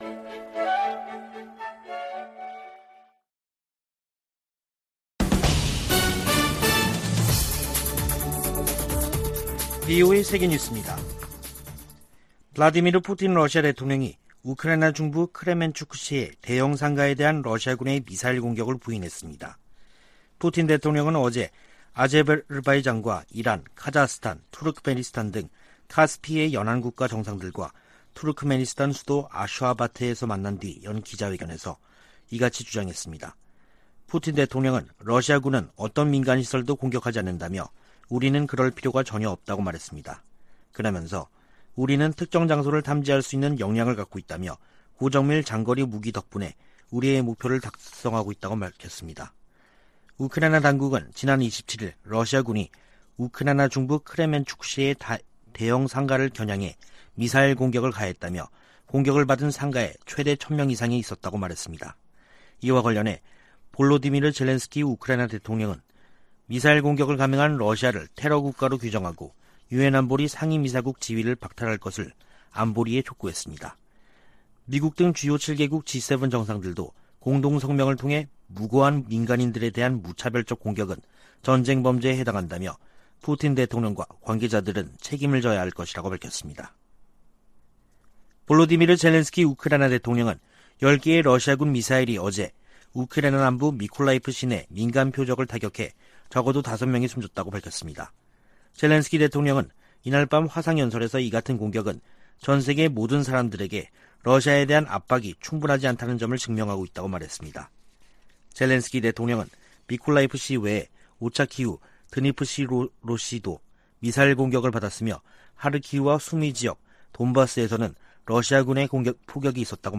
VOA 한국어 간판 뉴스 프로그램 '뉴스 투데이', 2022년 6월 30일 3부 방송입니다. 백악관은 조 바이든 대통령이 한국·일본 정상과 협력 심화를 논의한 역사적인 회담을 열었다고 발표했습니다. 미 공화당 상원의원들은 나토가 중국과 러시아의 위협에 동시에 대응하도록 할 것을 바이든 대통령에게 촉구했습니다. 냉각된 한일관계에 개선은 대북 억제와 중국 견제를 위해 중요하다고 미국 전문가들이 진단했습니다.